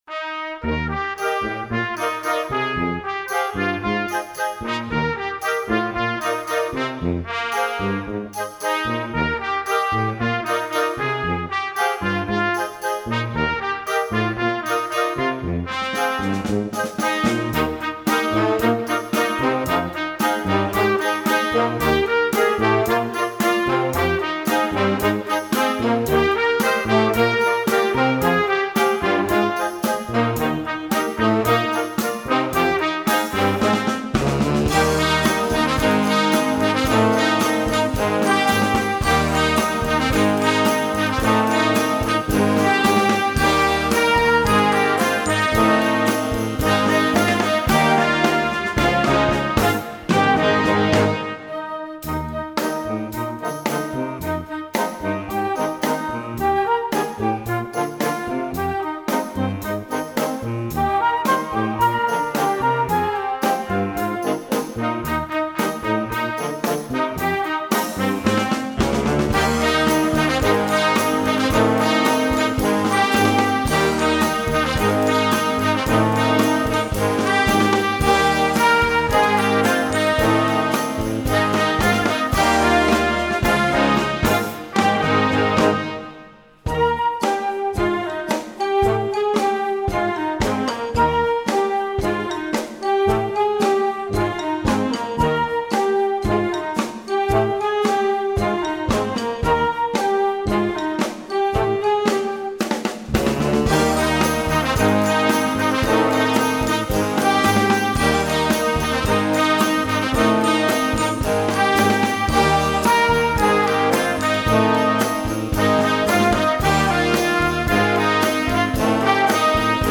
Gattung: Jugendwerk
Besetzung: Blasorchester
vibrant melody staged over a driving rock beat